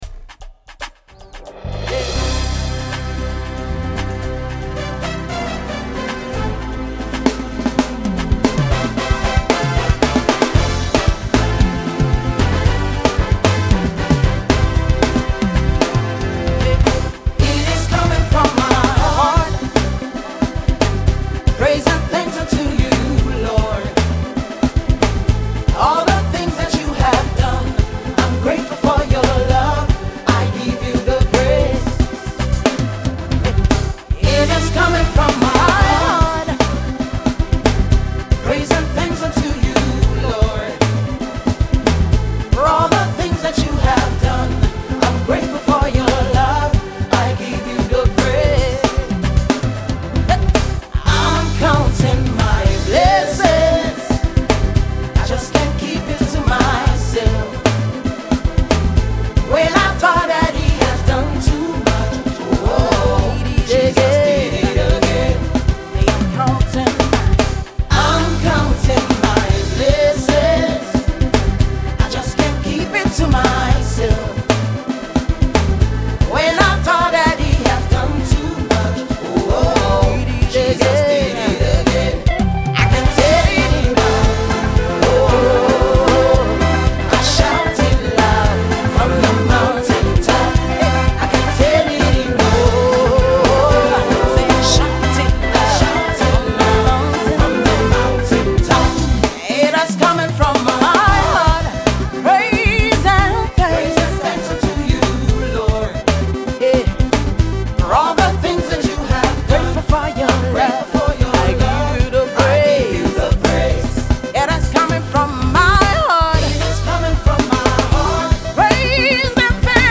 Gospel
highly spiritual tune